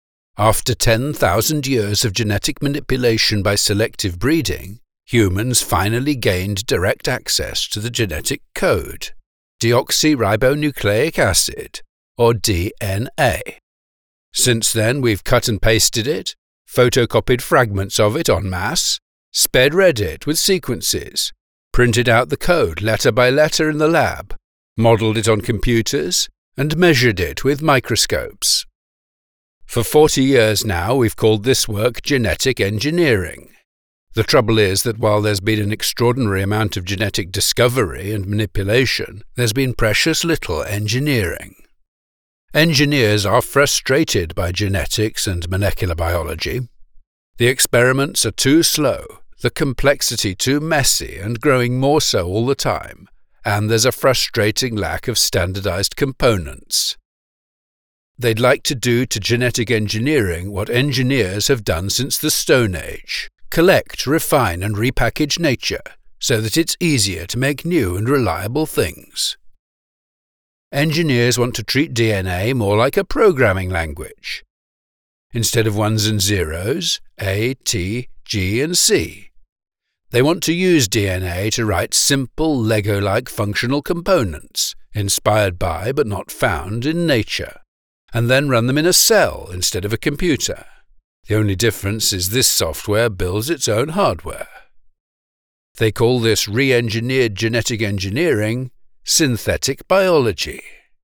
British Voiceover for Medical Narration: